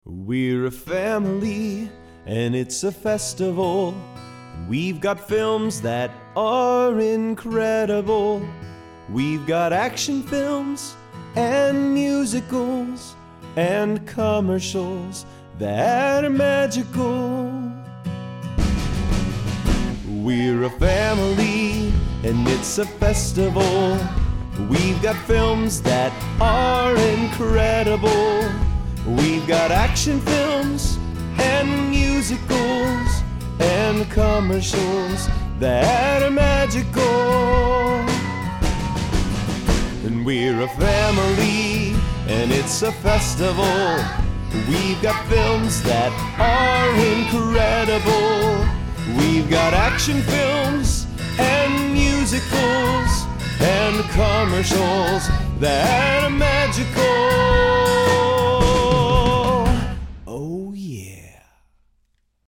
• Part One: the Family Film Fest jingle mp3